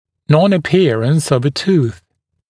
[ˌnɔnə’pɪərəns əv ə tuːθ][ˌнонэ’пиэрэнс ов э ту:с]ретенция зуба («непоявление», непрорезывание)